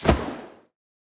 throw-projectile-5.ogg